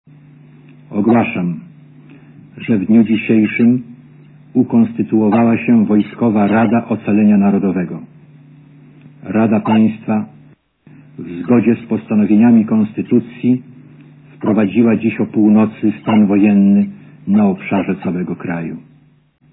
Le dicours de Jaruzelski a été diffusé en boucle à partir de six heures sur les radios et à la télévision.
jaruzelski.wav